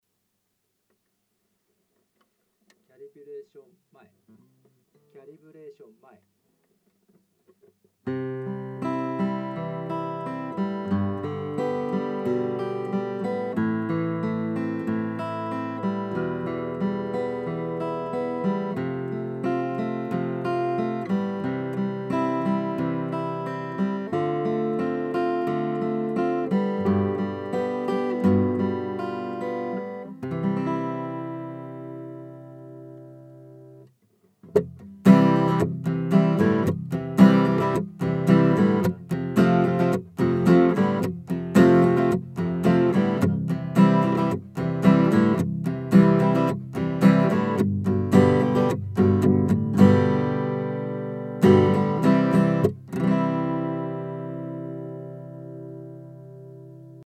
直接オーディオIFに入れてエフェクト類は全くかけていません。
iRigを購入した時のままギターに取付けた時の音
曇った感じの、まあ、言っちゃ何ですがひどい感じ
キャリブレーション前